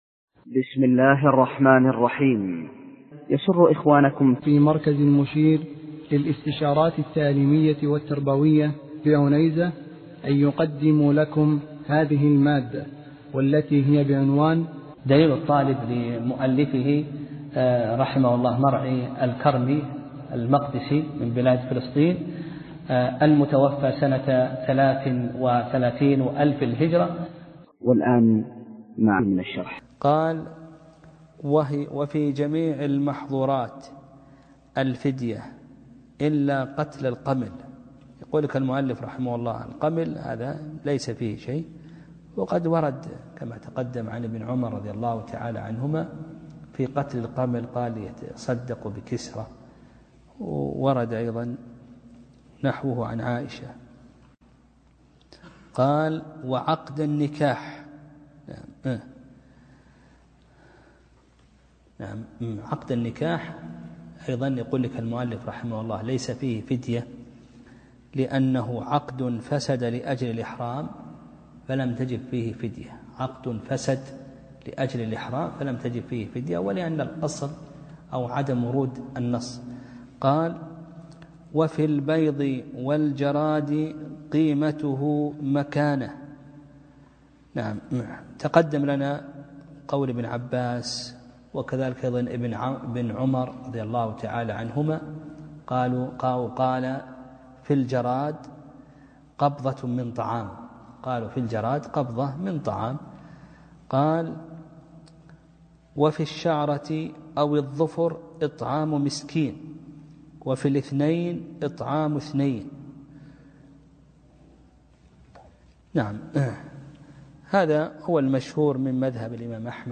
درس (27) : كتاب الحج: تتمة باب محظورات الإحرام وباب الفدية